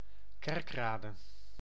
Kerkrade (Dutch pronunciation: [ˈkɛrkˌraːdə]